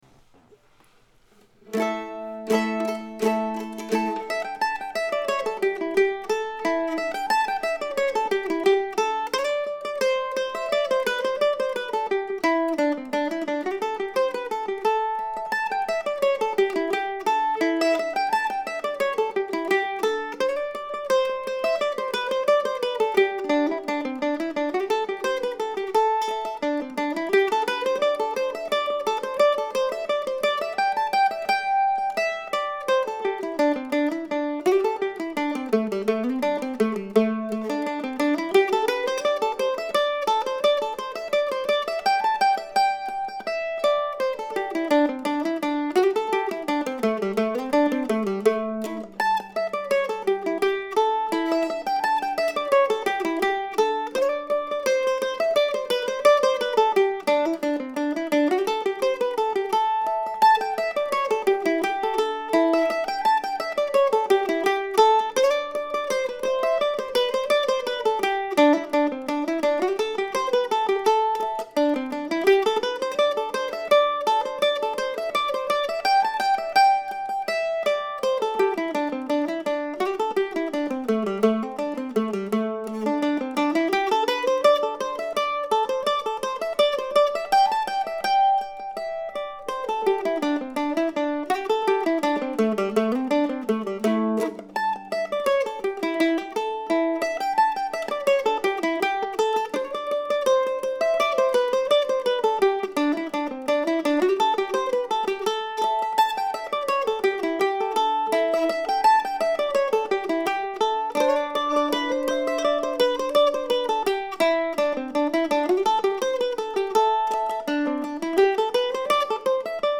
Today I'm presenting a stripped-down, solo mandolin version of Matildaville, from our visit to Great Falls National Park on the Potomac in March.